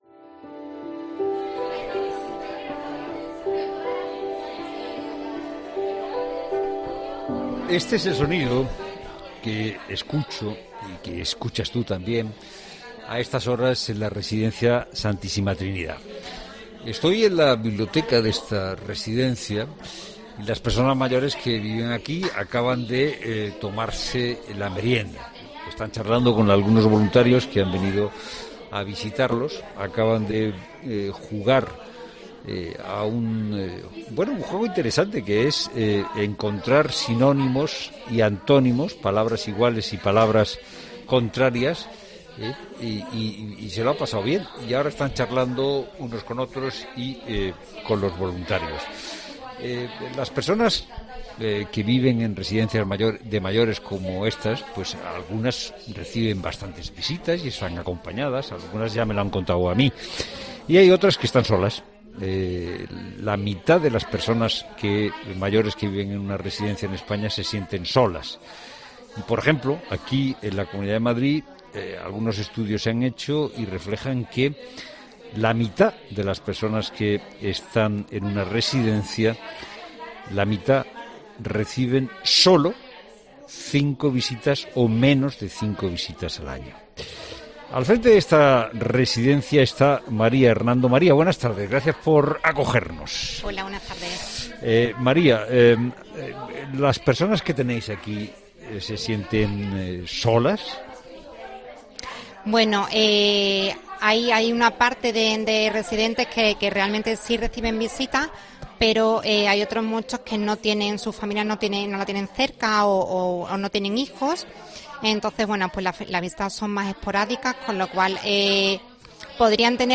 'La Tarde' visita una residencia de mayores en Madrid para comprobar de primera mano cómo afrontan los mayores la soledad, especialmente en los...